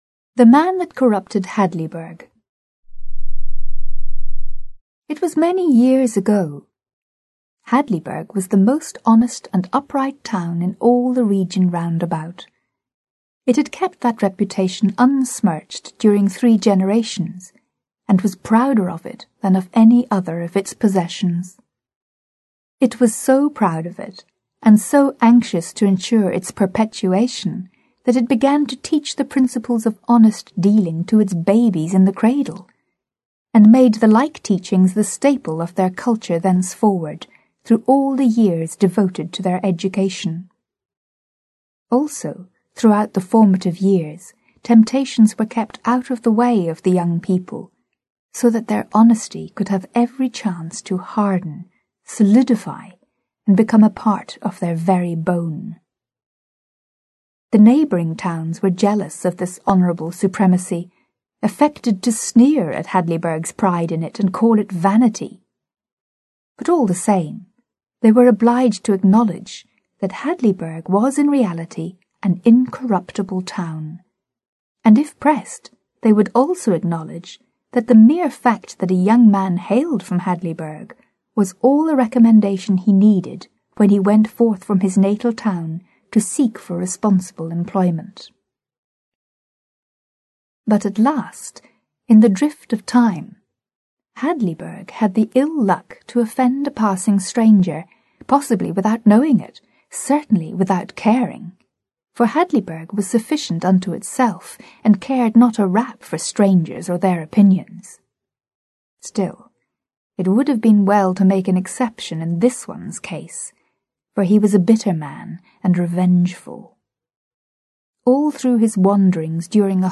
Аудиокнига The Man That Corrupted Hadleyburg | Библиотека аудиокниг